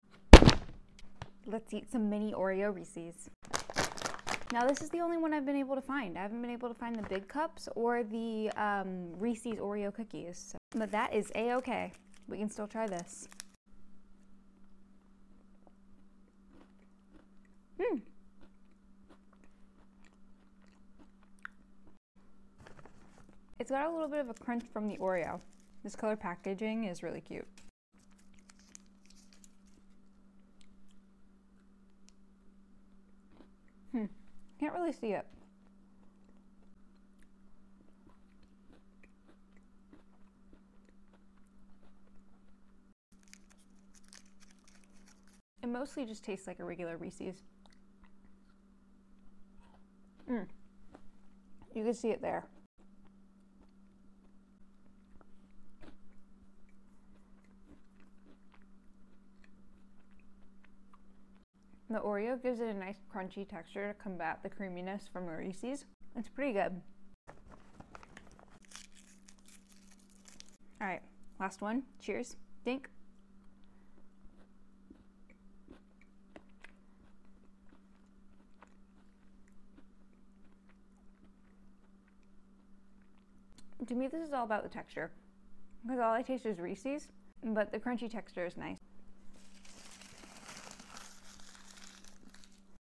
Oreo Reese’s Mukbang!